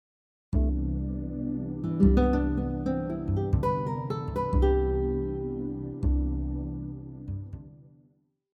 Now, we’ll incorporate the arpeggio with other scale and chromatic notes.